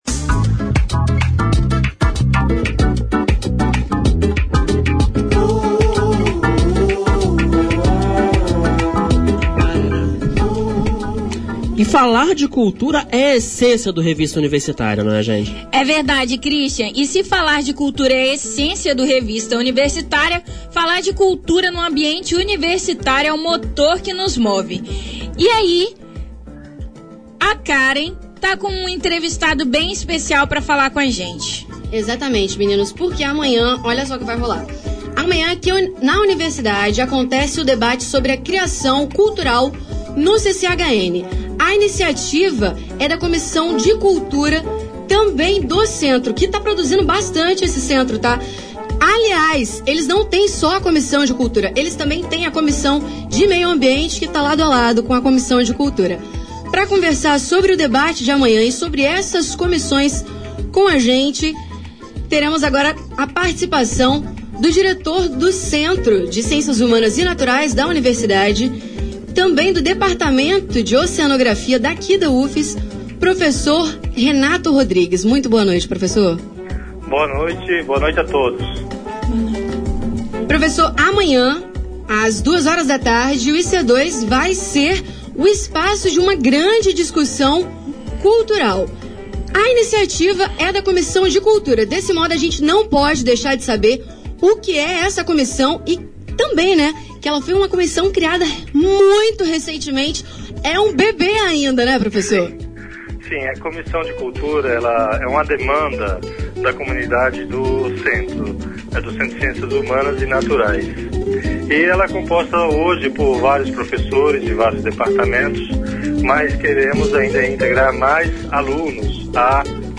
debate_final_ao_vivo.mp3